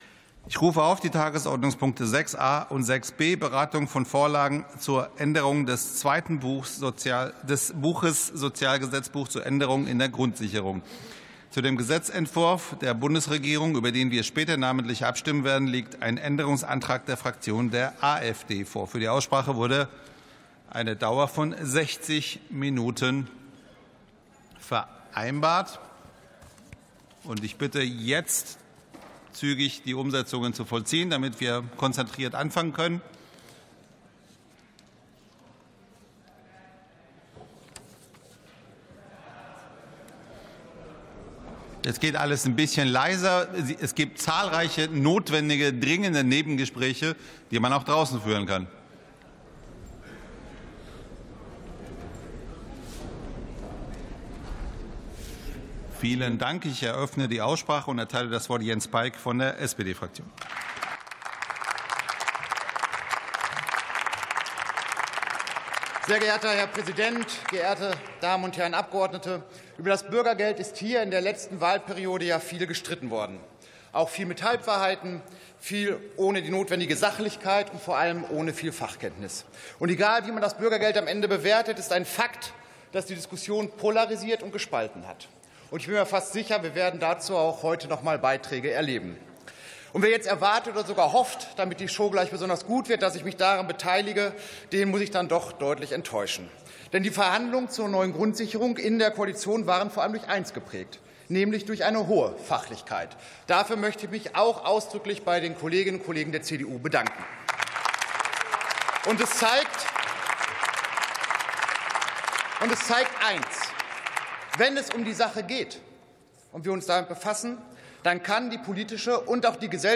Plenarsitzungen - Audio Podcasts